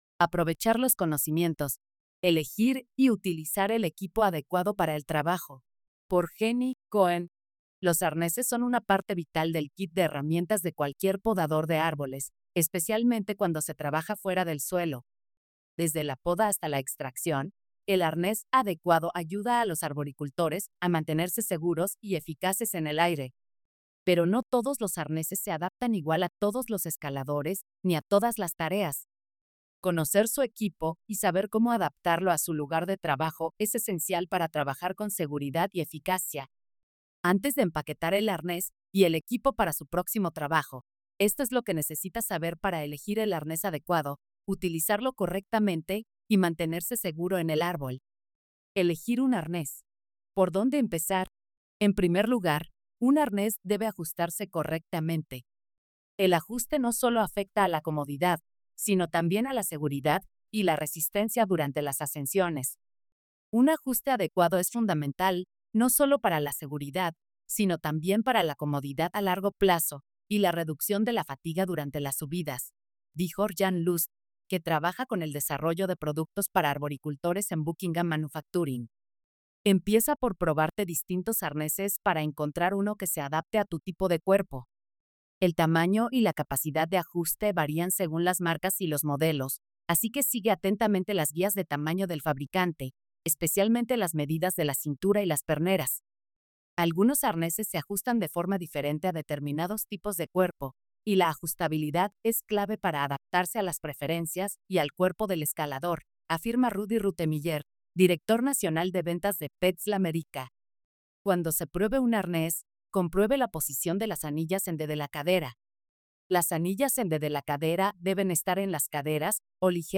Este es nuestro intento de convertir las historias en audio español usando Inteligencia Artificial.